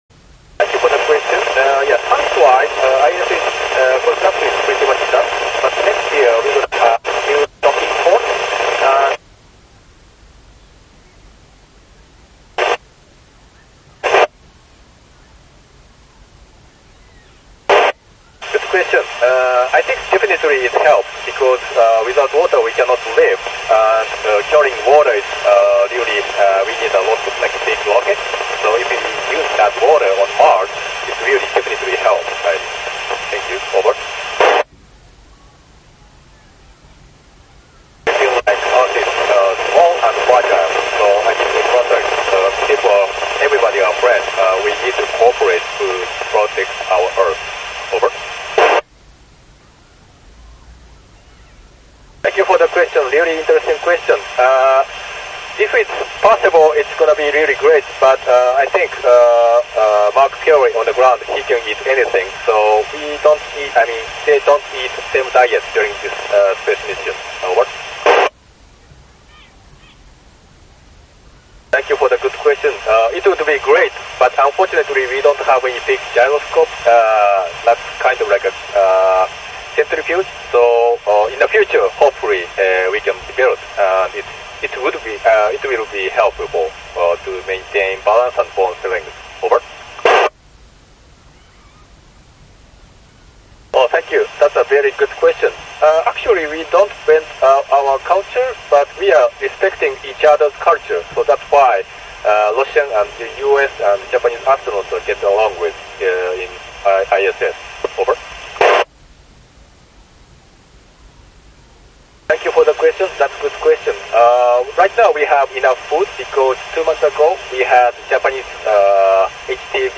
West Michigan Aviation Academy, Grand Rapids, MI, direct via W8ISS
I was able to record some of the transmission from ISS starting around question 3.